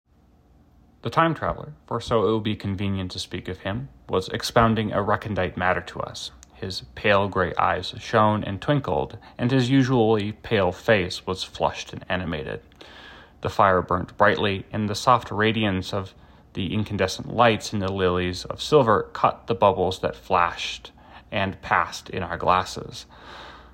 第一个样本是对现实生活中的人，您不起眼的秃鹰的录制，从H.G. Wells的《 Time Machine》中读到，而第二个是AI生成的克隆人从Jules Verne的20,000个海底的联赛中阅读。
人类样本：